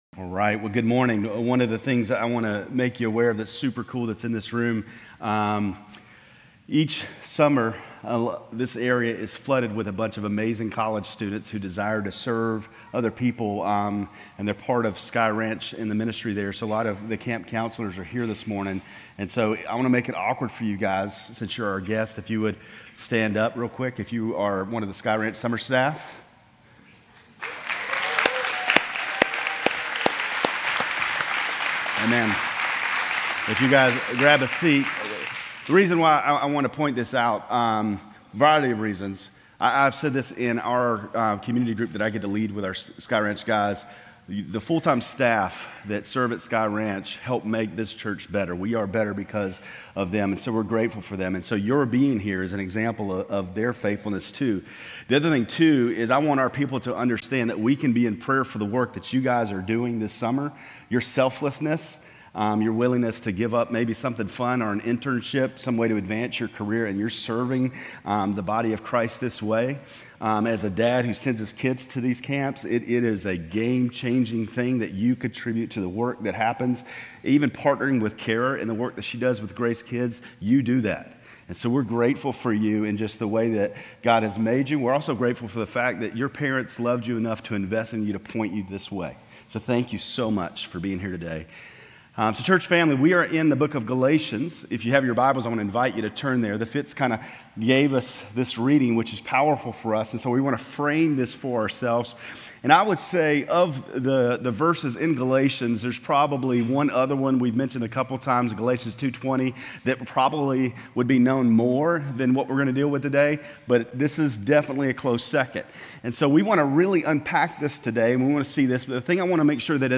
Grace Community Church Lindale Campus Sermons Galatians 5:16-26 Jun 03 2024 | 00:26:07 1x 00:00 / 00:26:07 Subscribe Share RSS Feed Share Link Embed